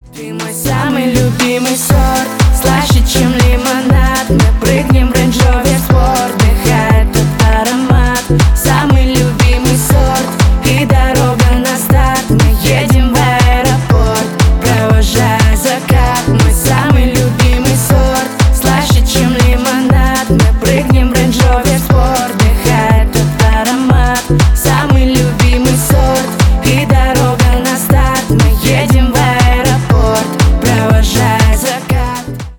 поп
женский вокал